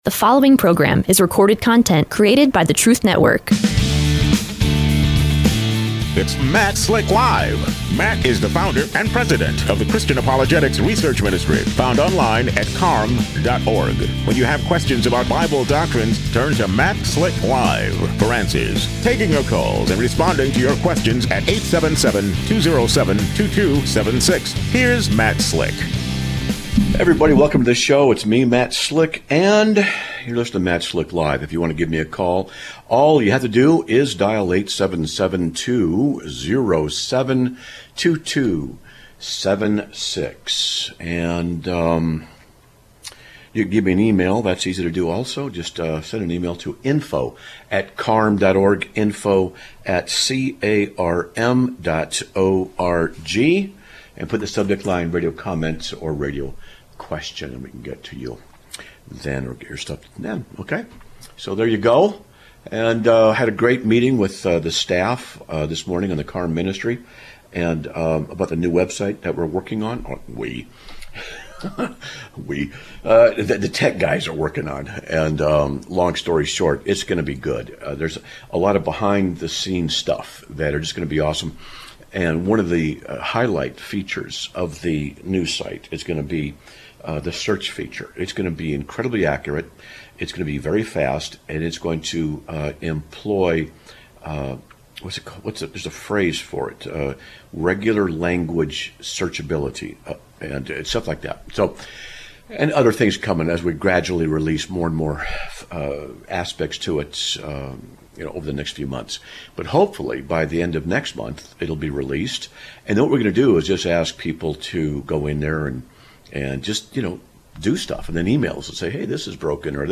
Live Broadcast of 07/23/2025